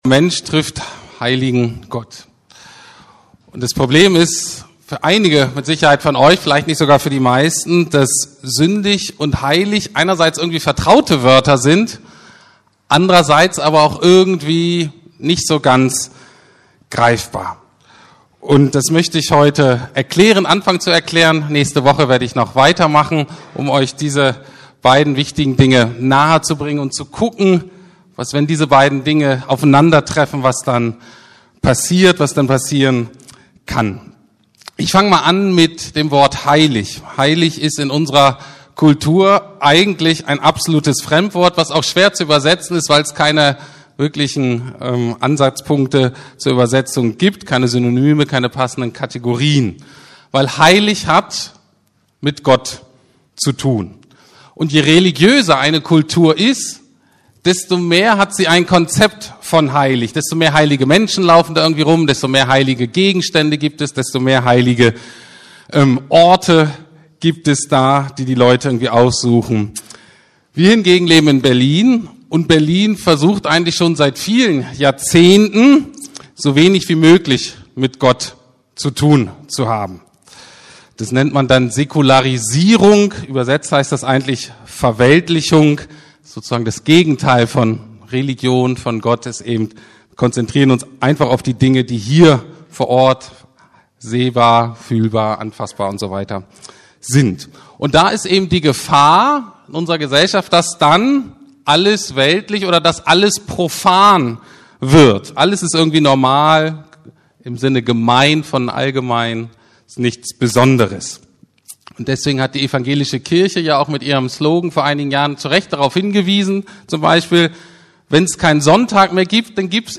Mensch trifft Heiligen Gott ~ Predigten der LUKAS GEMEINDE Podcast